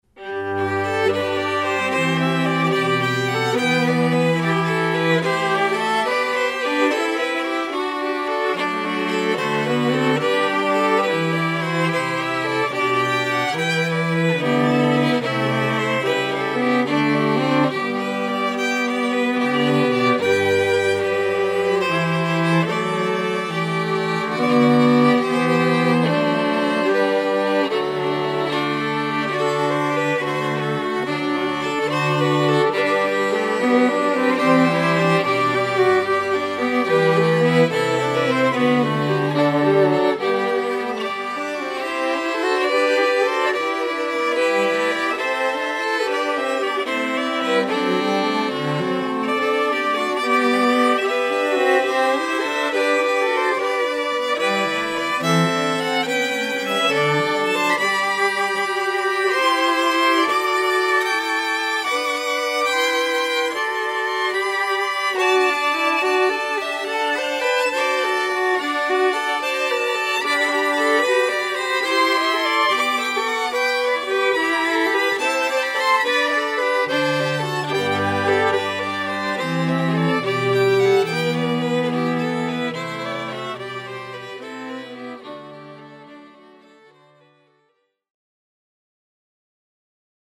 (Two Violins, Viola, & Cello)